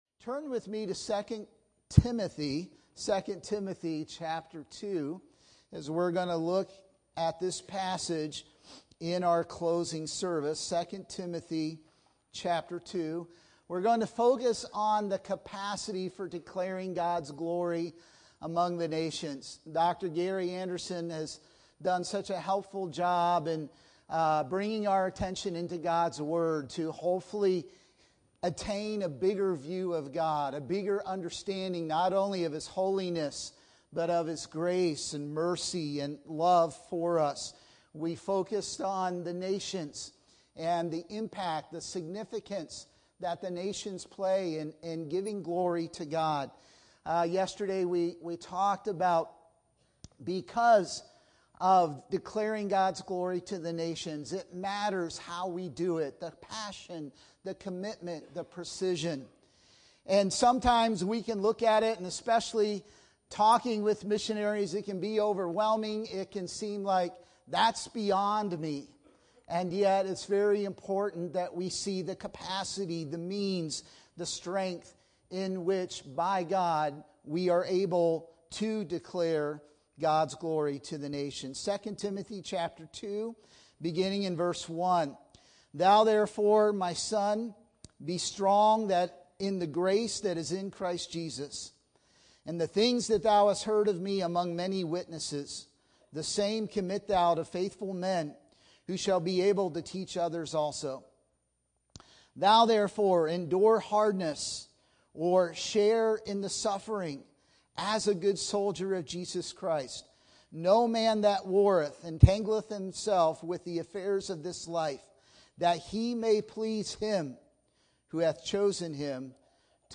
Chapel Message